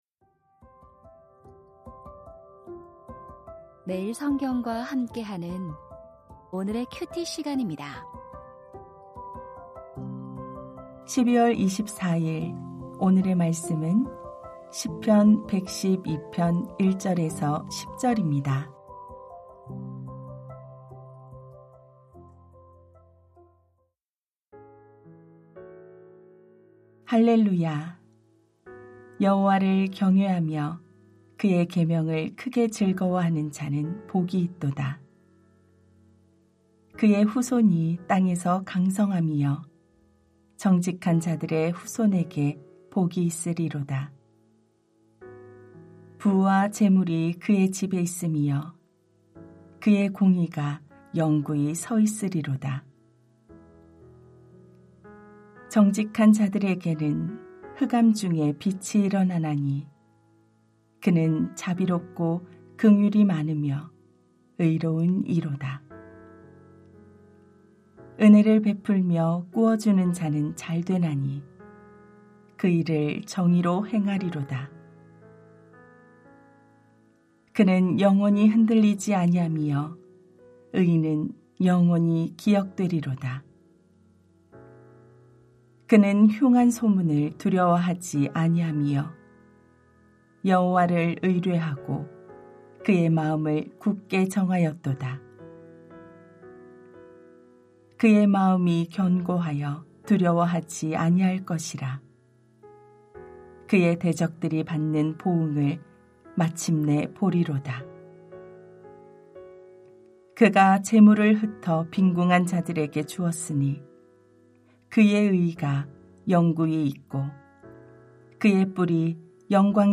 시편 112:1-10 시편의 복된 인생은? 2024-12-24 (화) > 오디오 새벽설교 말씀 (QT 말씀묵상) | 뉴비전교회